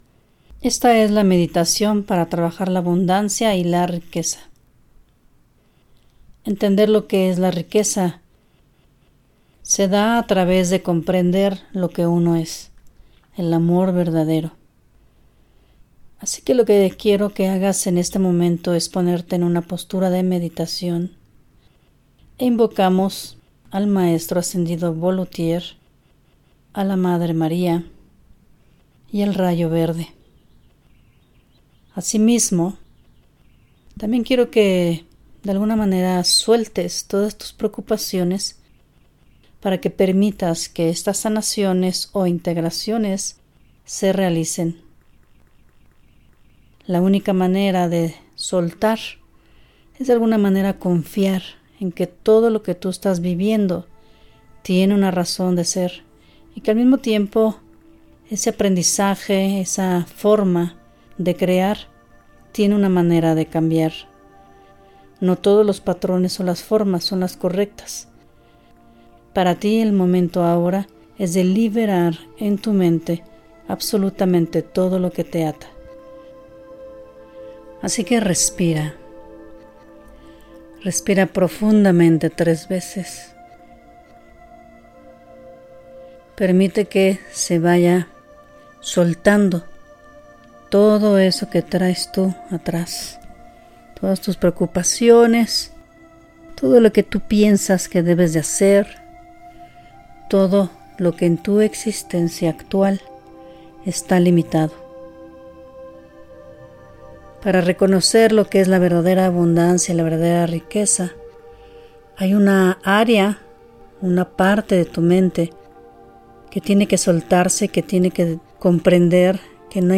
Grabado: En América en Ascensión, Edo. de México, Junio 2025